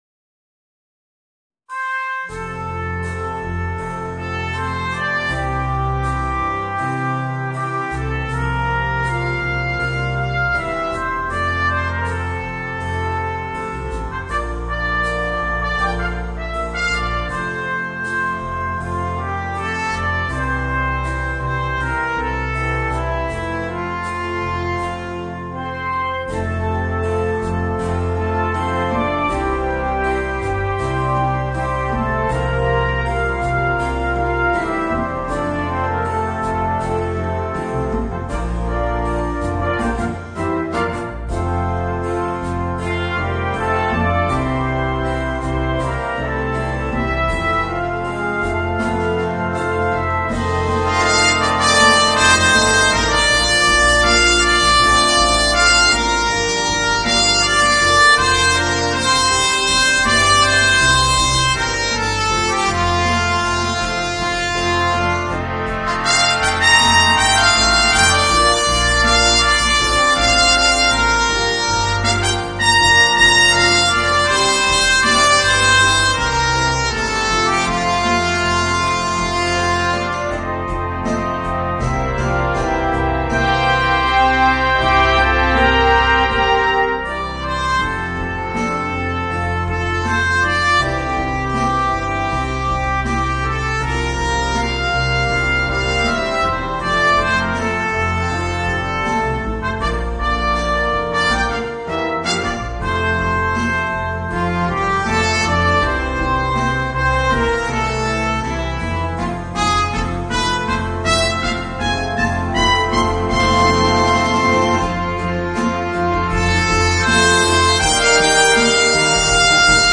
Voicing: Eb Horn and Brass Band